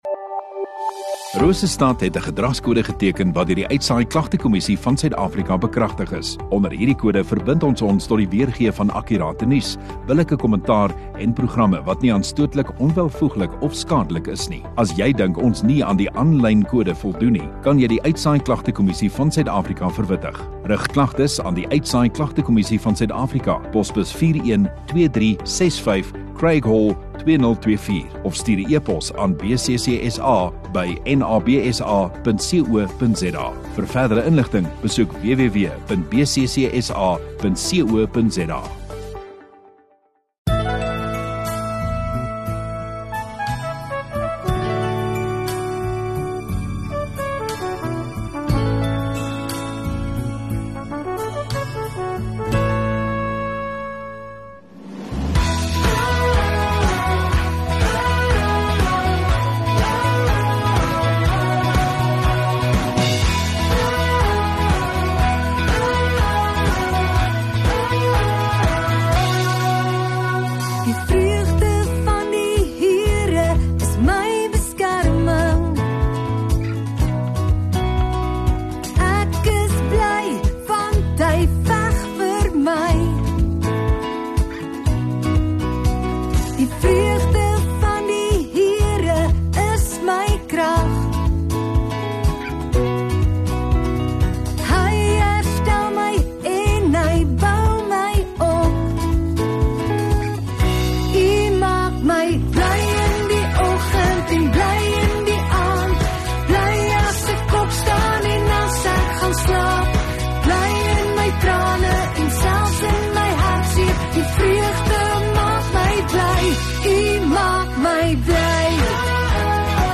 22 Dec Sondagoggend Erediens